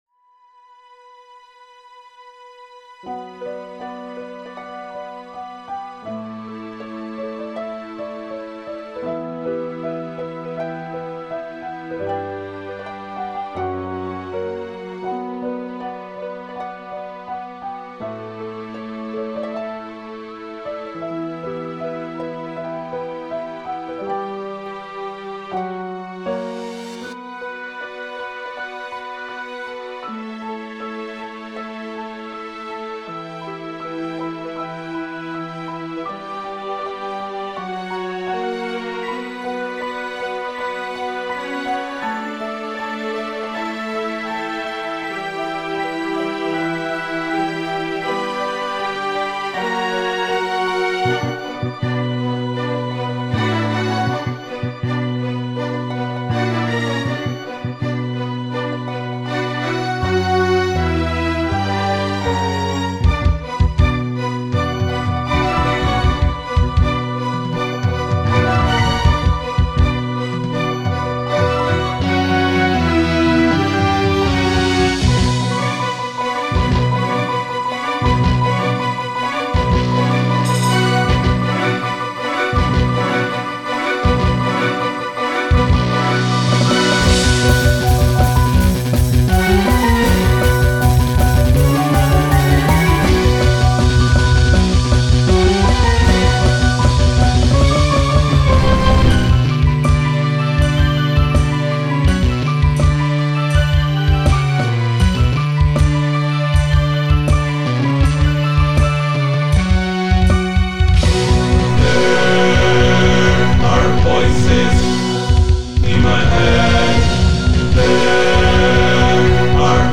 EstiloInstrumental